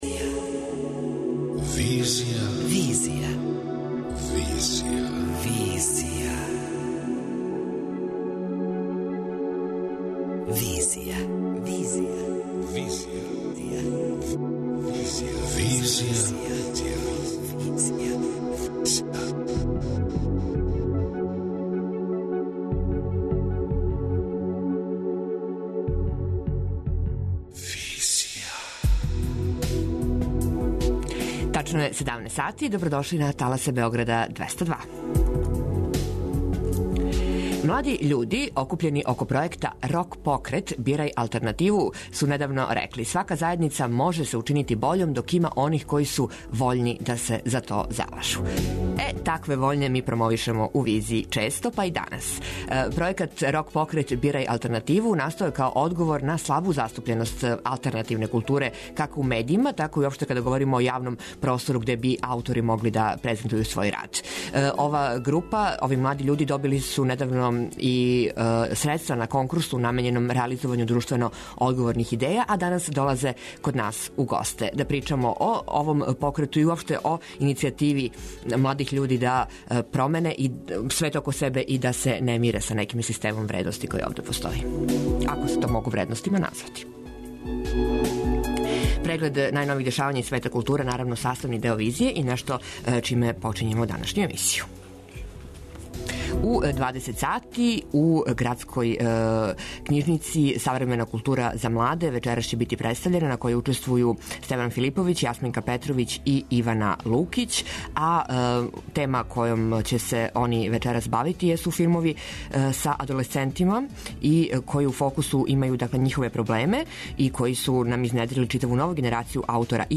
Данас су наши гости.